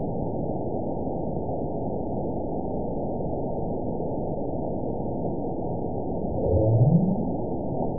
event 920306 date 03/14/24 time 22:24:08 GMT (1 year, 1 month ago) score 9.22 location TSS-AB02 detected by nrw target species NRW annotations +NRW Spectrogram: Frequency (kHz) vs. Time (s) audio not available .wav